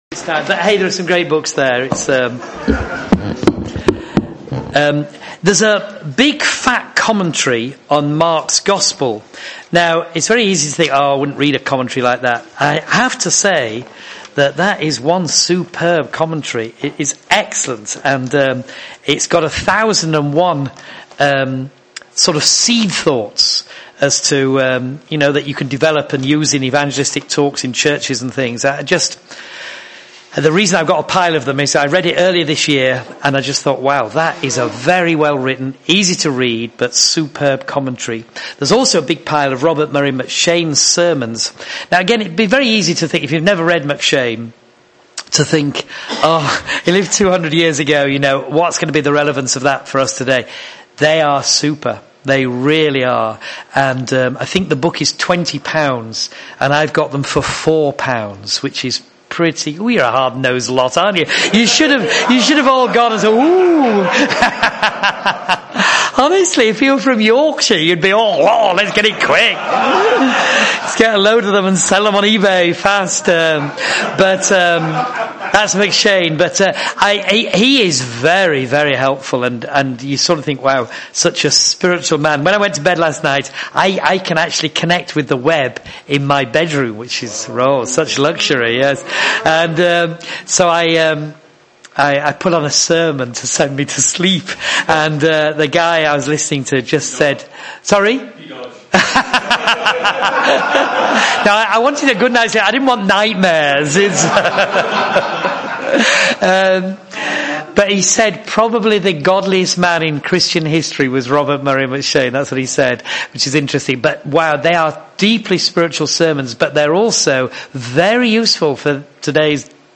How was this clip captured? Conference 2011